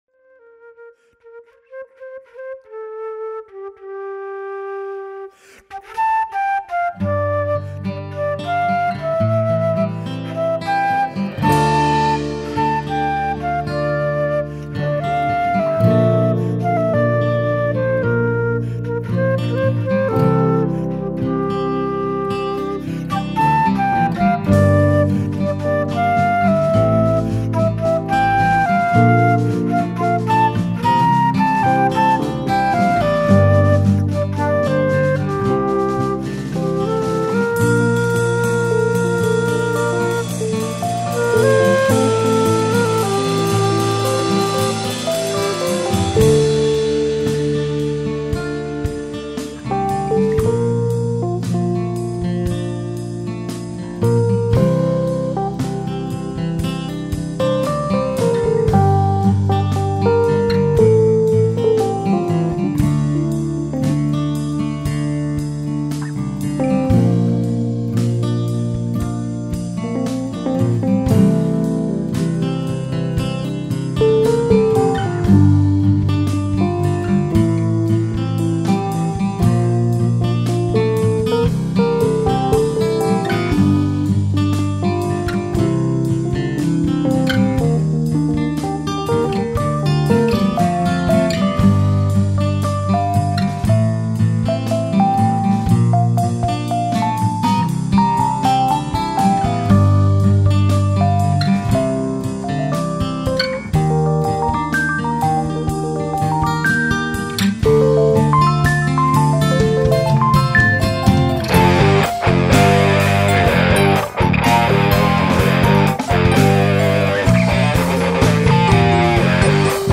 an epic tune and a terrific display of musicianship